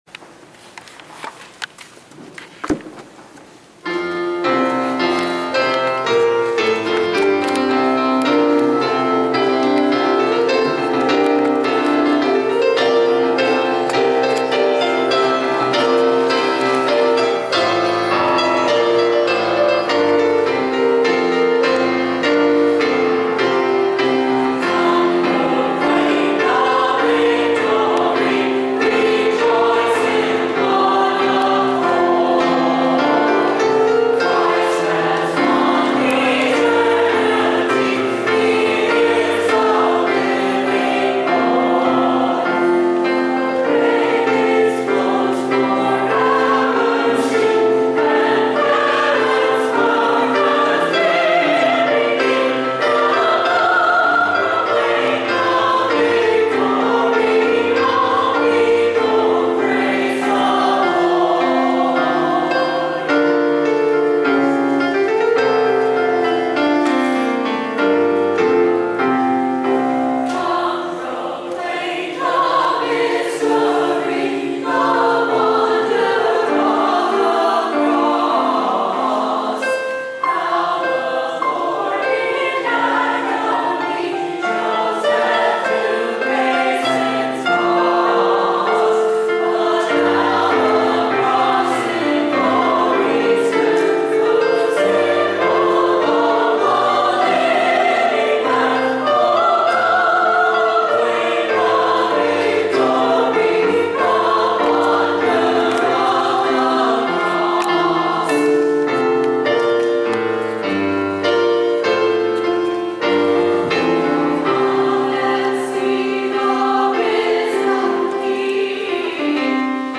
Musician Parent Sunday at AUMC
That was followed by a resounding choral anthem: Christ Has Won the Victory.